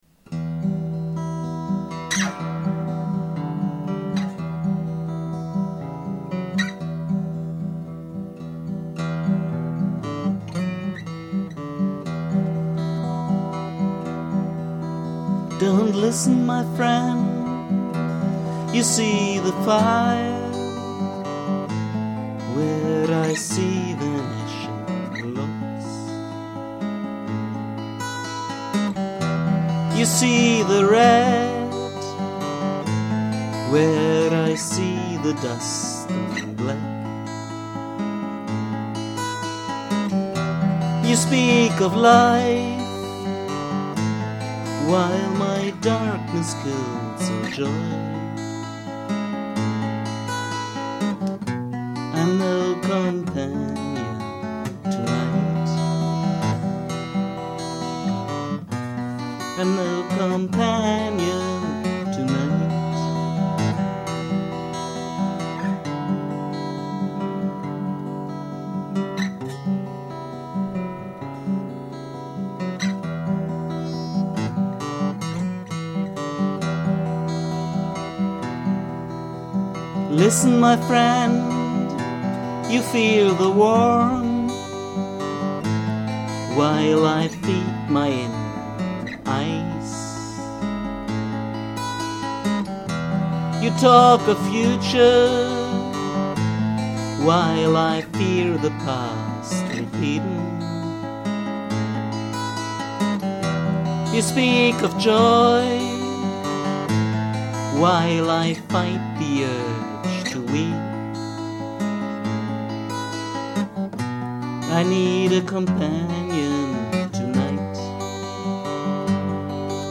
Not only a tune can turn from minor to major...